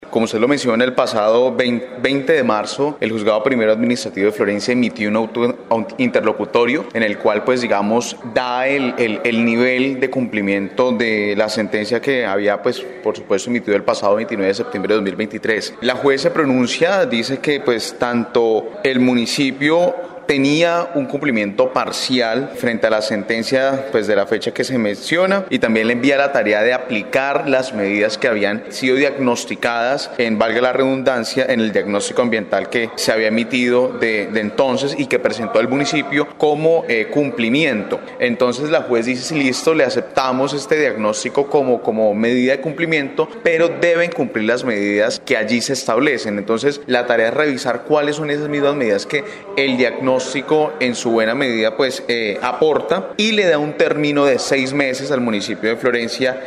El concejal Erick Mauricio Sánchez, del Partido Verde, explicó que, esta decisión se tomó después de que el juzgado revisara los informes presentados por las partes involucradas, incluyendo Corpoamazonia, los constructores y la alcaldía, indicando que el municipio, ha cumplido de manera parcial.
CONCEJAL_ERICK_SANCHEZ_FORJADORES_-_copia.MP3